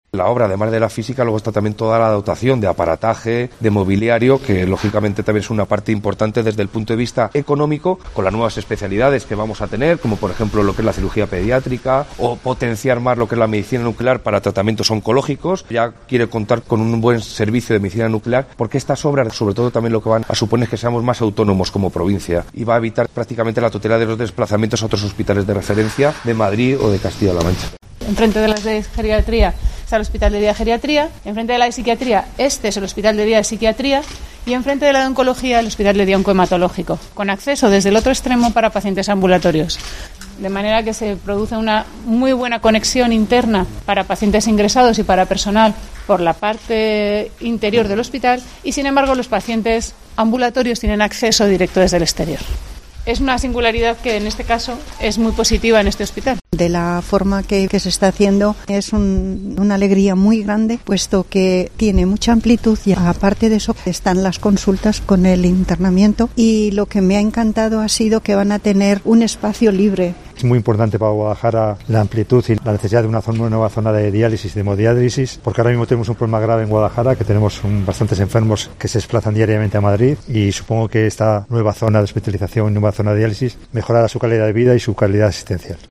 Escuchamos a los representantes de AFAUS Salud Mental y ALCER, Asociación de enfermos renales.